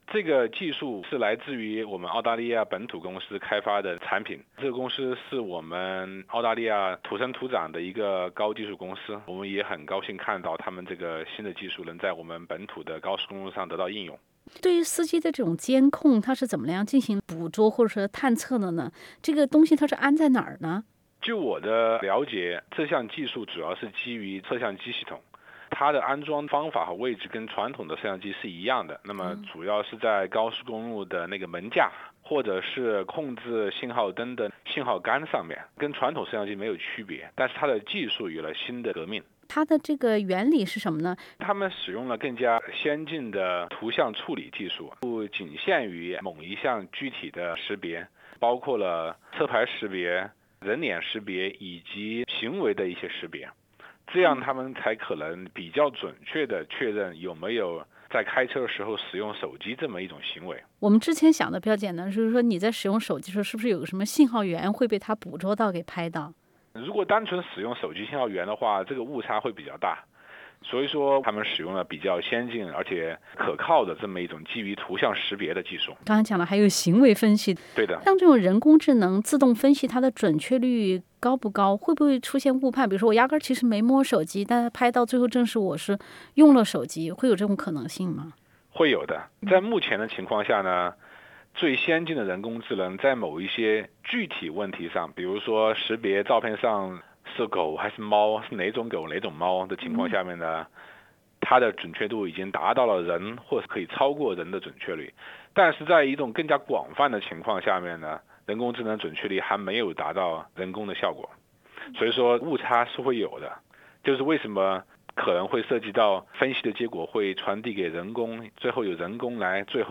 Source: Max pixel SBS 普通话电台 View Podcast Series Follow and Subscribe Apple Podcasts YouTube Spotify Download (10.05MB) Download the SBS Audio app Available on iOS and Android AI抓开车用手机行为的探测原理是什么？